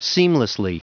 Prononciation du mot seamlessly en anglais (fichier audio)
Prononciation du mot : seamlessly